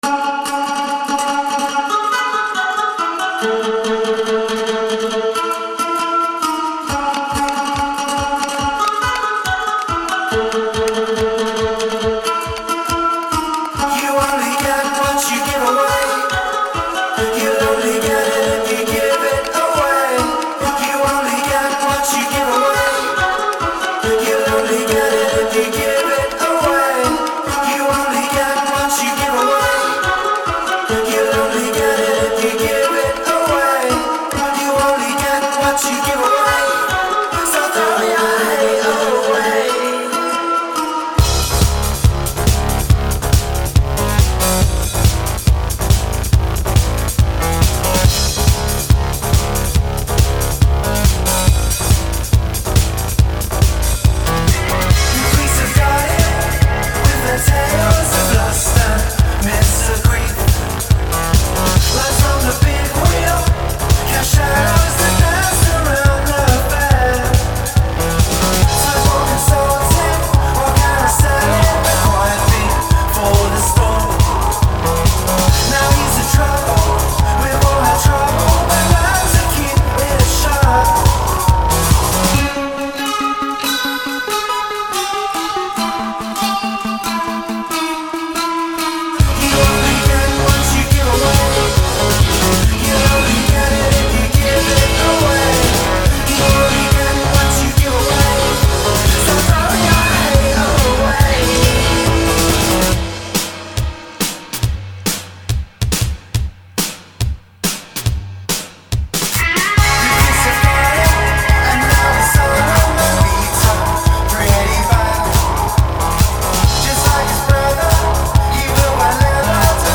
All very bright, booty-shaking, catchy, poptastical cuts.